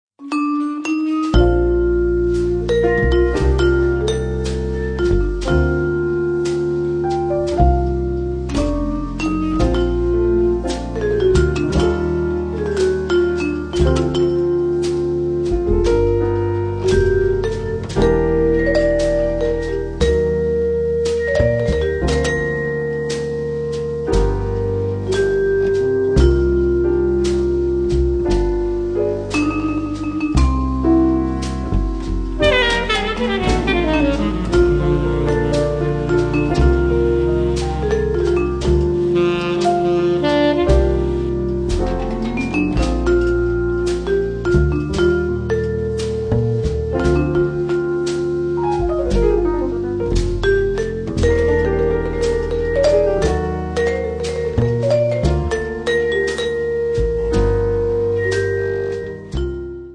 vibrafono
sassofono
pianoforte
contrabbasso
batteria